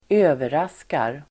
Uttal: [²'ö:veras:kar]